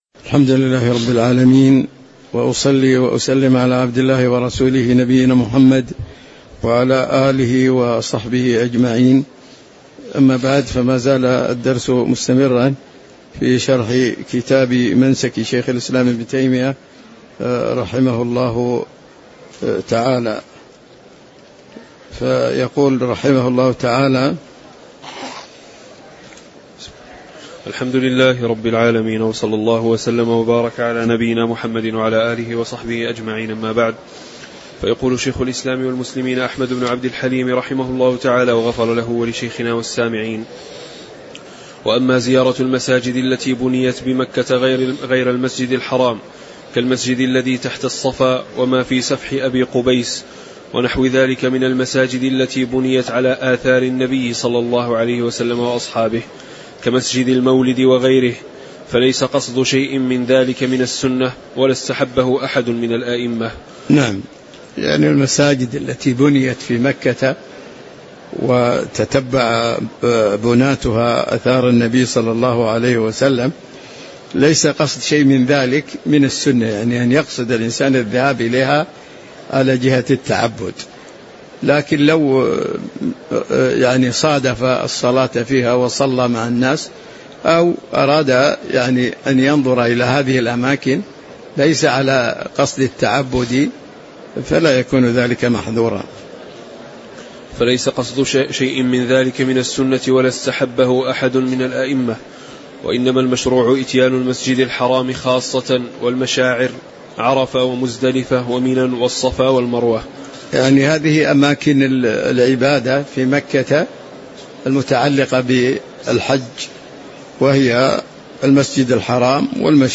تاريخ النشر ٣ ذو الحجة ١٤٤٦ هـ المكان: المسجد النبوي الشيخ